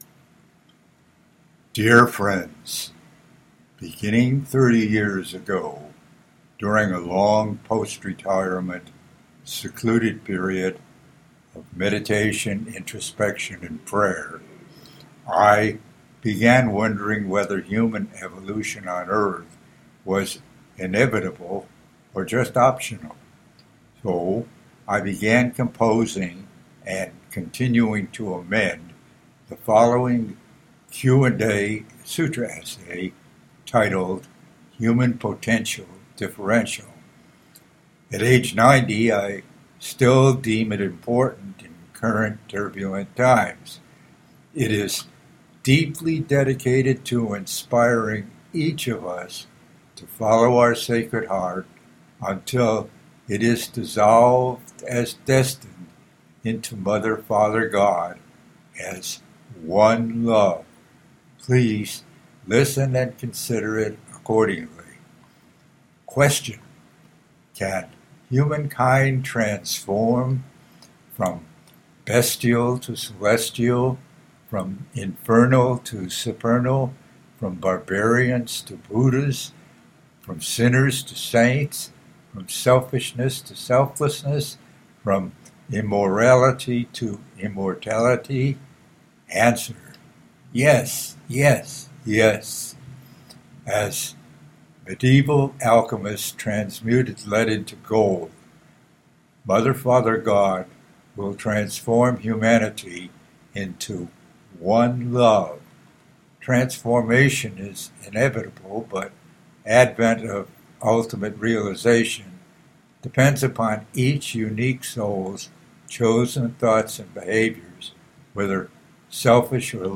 HUMAN POTENTIAL DIFFERENTIAL RECITATION
HUMAN-POTENTIAL-DIFFERENTIAL-RECITATION.mp3